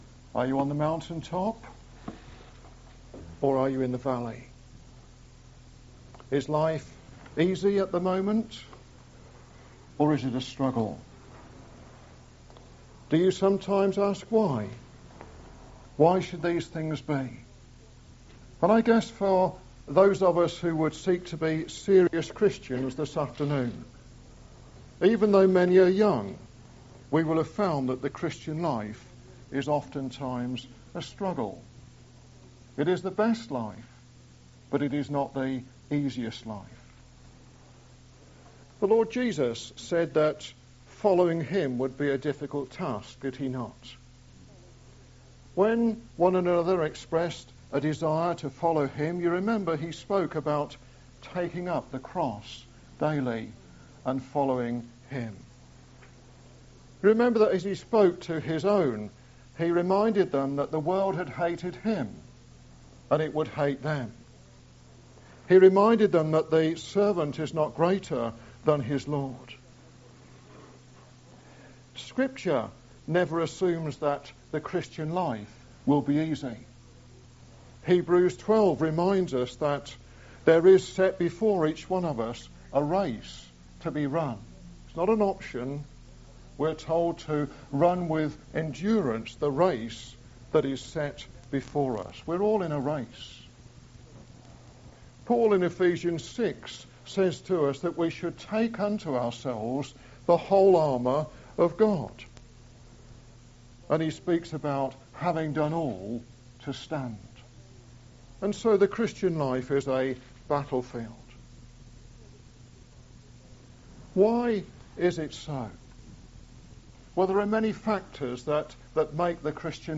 This message was preached at Osborne Road Gospel Hall, Northampton.